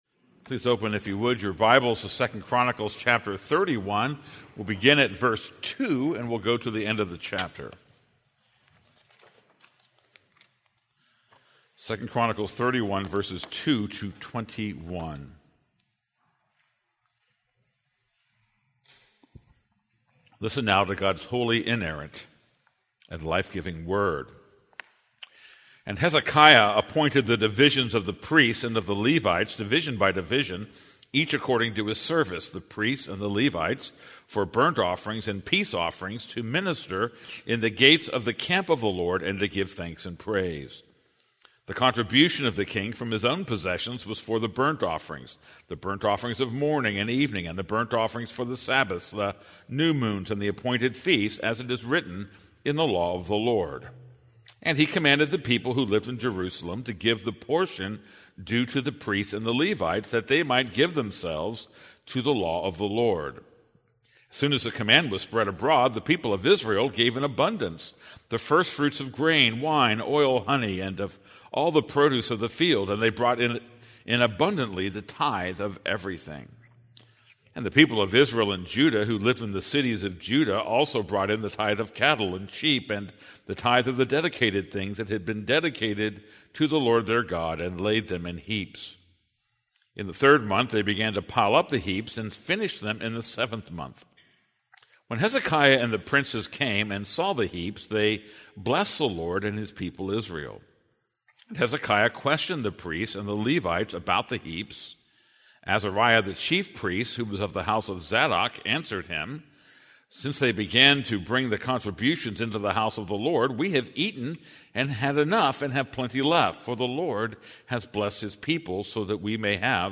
This is a sermon on 2 Chronicles 31:2-21.